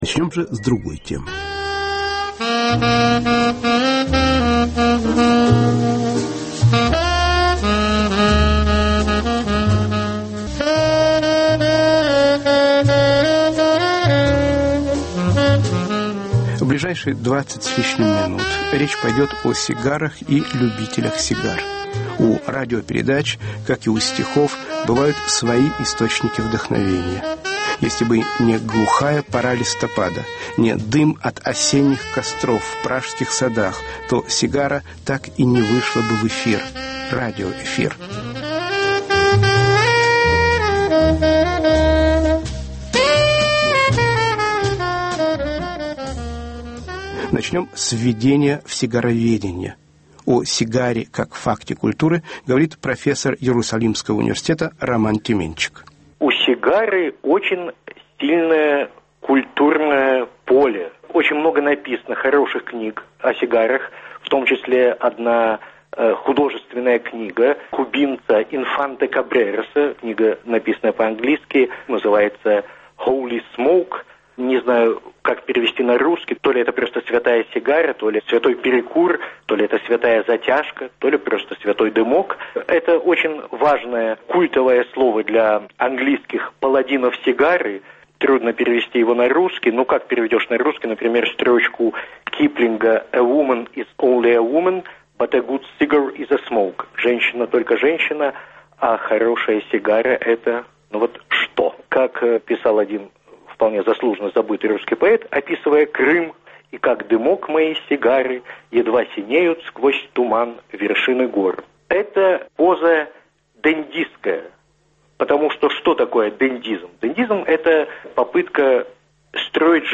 Образ сигары (передача с участием историка культуры, автора книги о сигарах, психолога и коллекционера сигар)